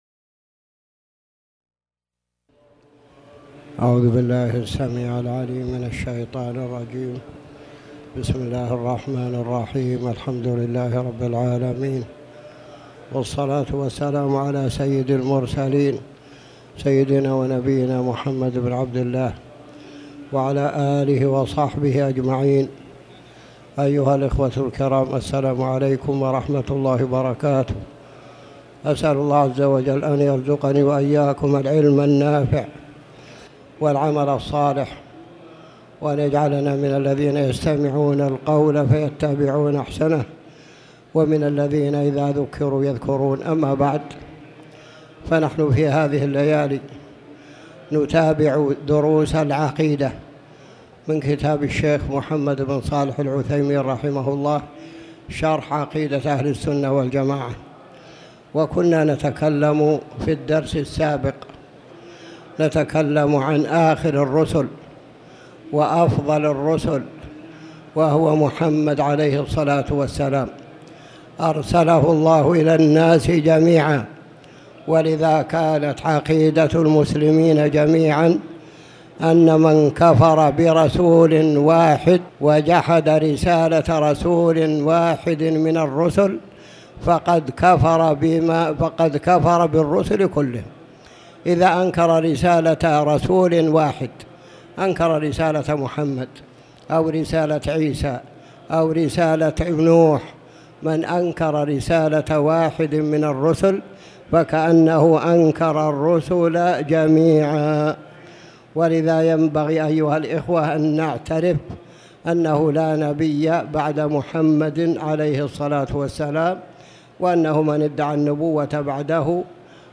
تاريخ النشر ٢٩ صفر ١٤٣٩ هـ المكان: المسجد الحرام الشيخ